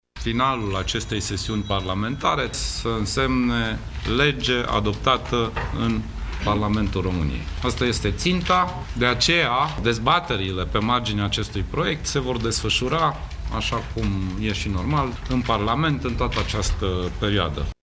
Premierul Sorin Grindeanu a mulțumit echipei care a lucrat la acest proiect pentru că au reușit să-l finalizeze în timp util pentru a putea fi adoptat în Parlament până la sfârțitul acestei sesiuni parlamentare: